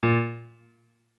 MIDI-Synthesizer/Project/Piano/26.ogg at 51c16a17ac42a0203ee77c8c68e83996ce3f6132